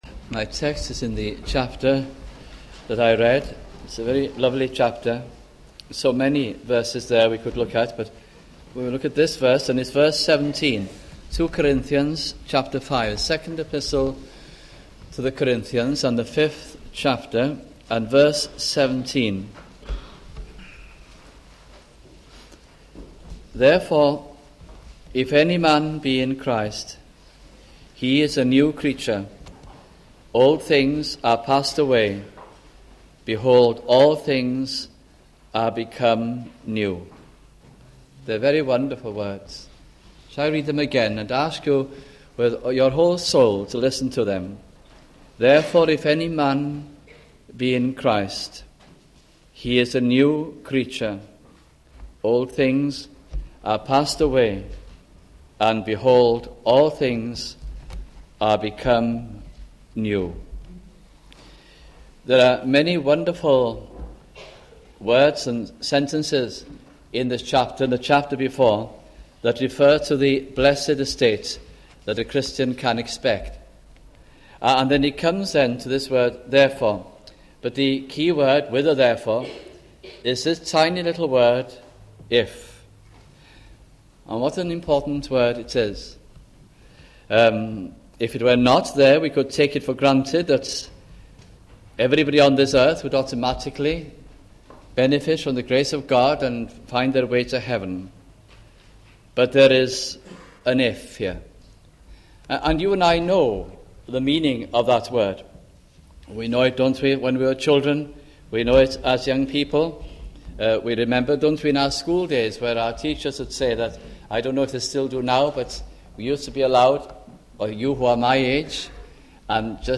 » 2 Corinthians Gospel Sermons